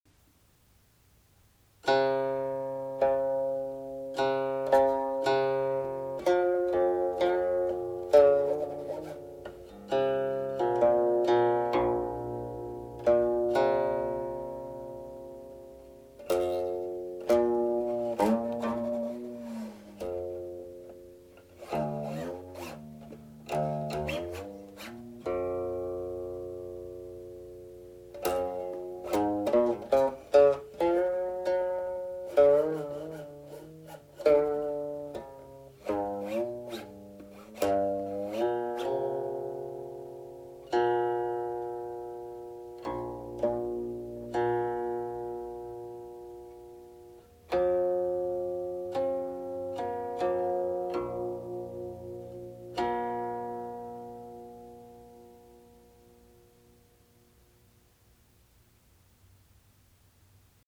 As for the illustration above, the right side of the image shows the original tablature, in this case Shang Yi, the shang mode prelude from Taiyin Daquanji; the left side has the finished product in staff notation.
listen while viewing the notation you will see differences that resulted from, after doing the recording, more carefully comparing this version with later shang mode preludes.